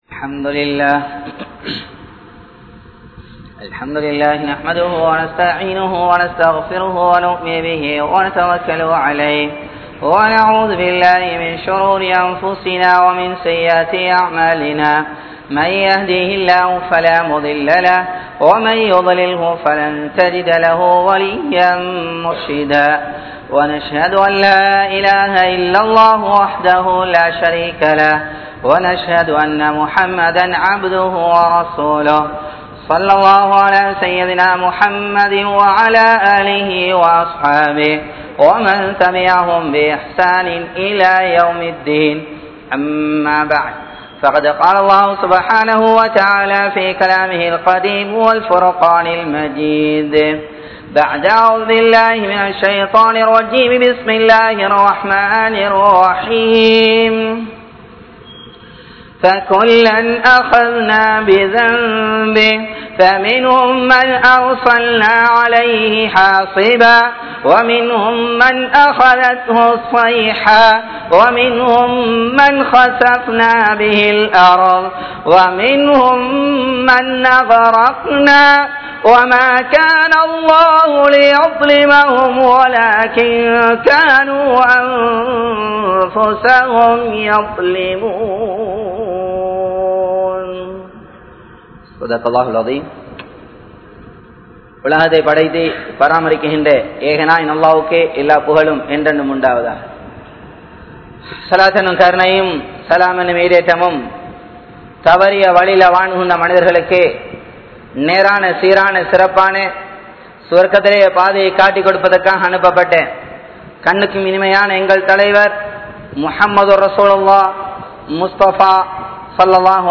Paavaththai Vittu Vidungal (பாவத்தை விட்டு விடுங்கள்) | Audio Bayans | All Ceylon Muslim Youth Community | Addalaichenai
Colombo 12, Aluthkade, Muhiyadeen Jumua Masjidh